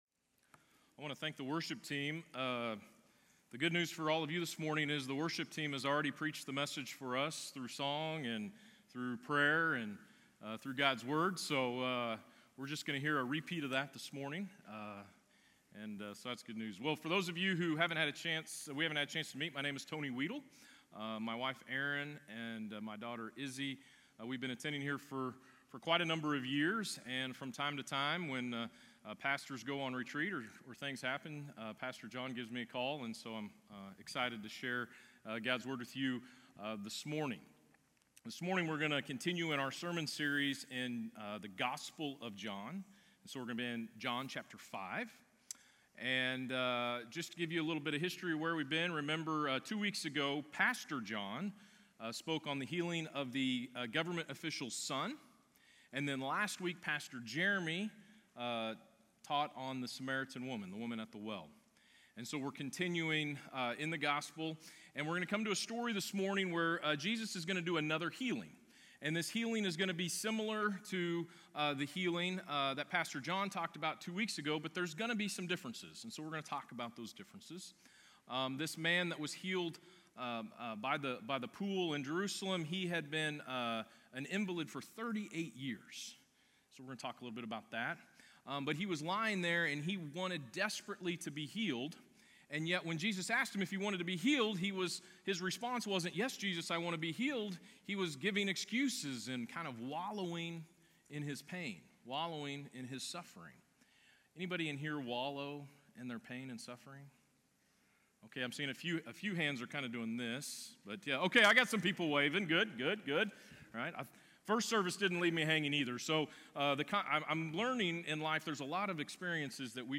Sermons | Countryside Covenant Church